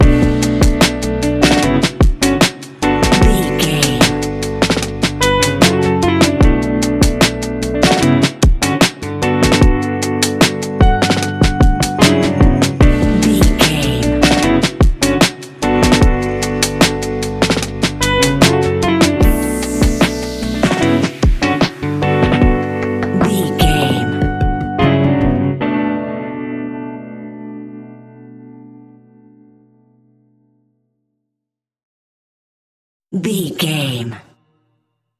Ionian/Major
D♭
chilled
laid back
Lounge
sparse
new age
chilled electronica
ambient
atmospheric
morphing
instrumentals